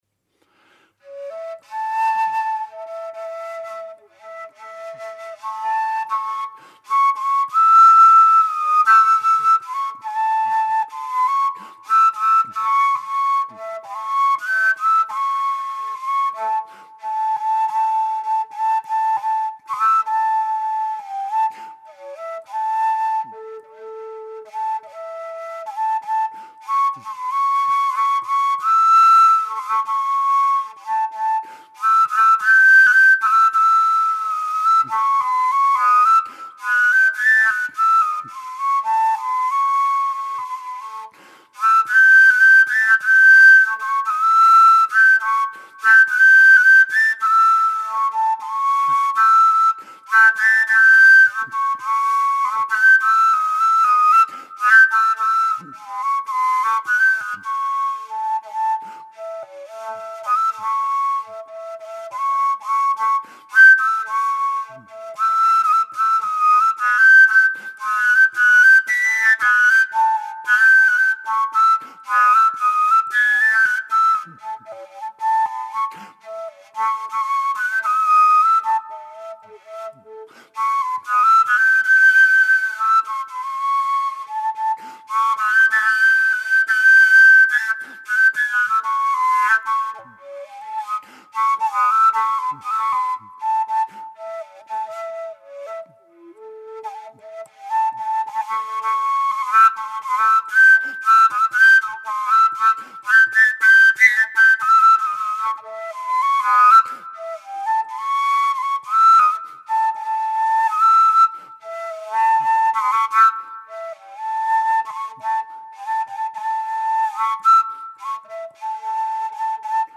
Harmonic Overtones Flutes
Listen to low A improvisation on low Ab (:audioplayer